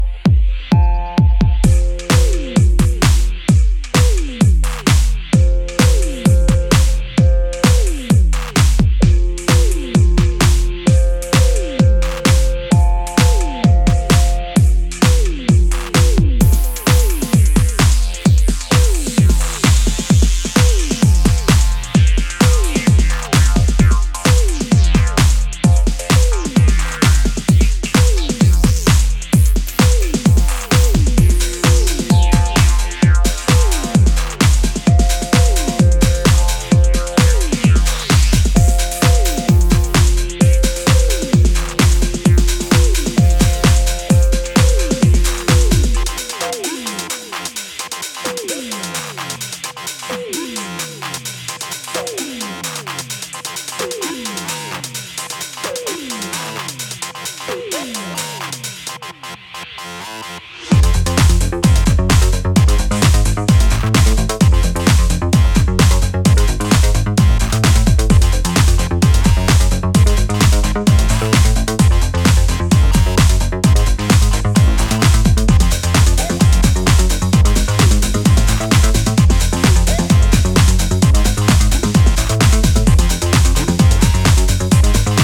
techno. With pulsating beats and intricate soundscapes